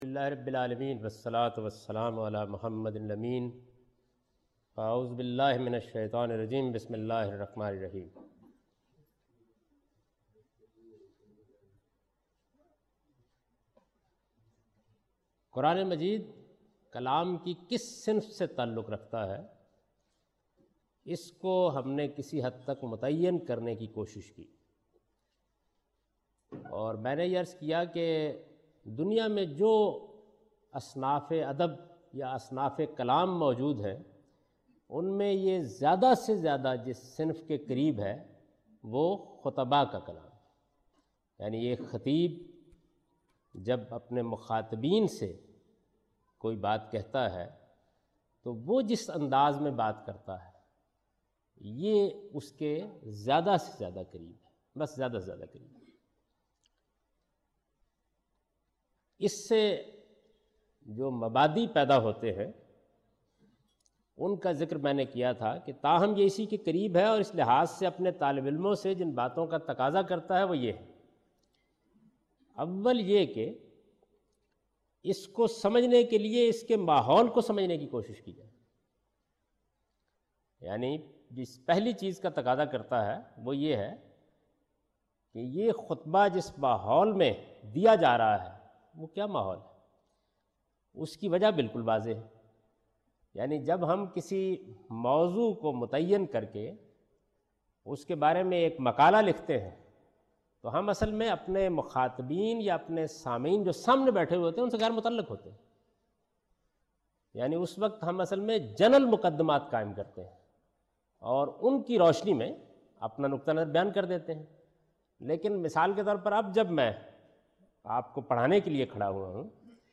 A comprehensive course on Islam, wherein Javed Ahmad Ghamidi teaches his book ‘Meezan’.
In this lecture he teaches the importance of uniqueness of style and final authority of Quran in order to truly understand the book. (Lecture no.20 – Recorded on 23rd March 2002)